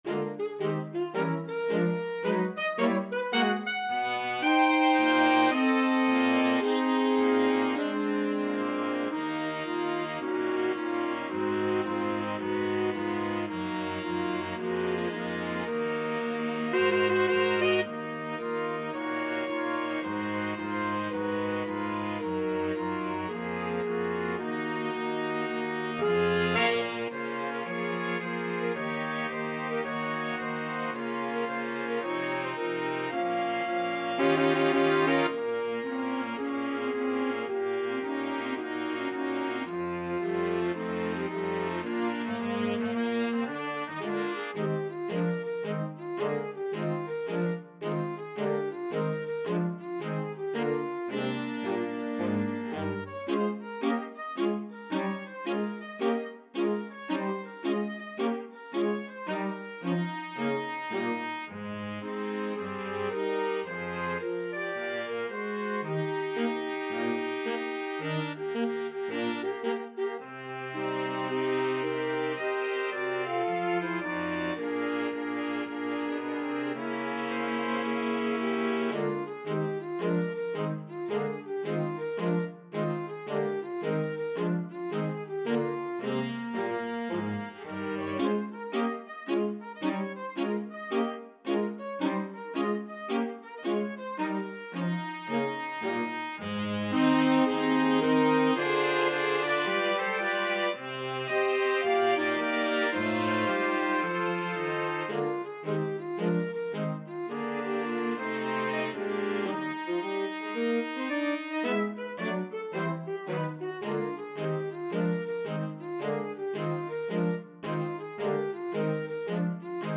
Alto SaxophoneFluteClarinet
TrumpetFluteFrench HornClarinet
TrumpetFrench HornBassoonBaritone SaxophoneTenor Saxophone
Moderato =110 swung
4/4 (View more 4/4 Music)